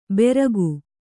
♪ beragu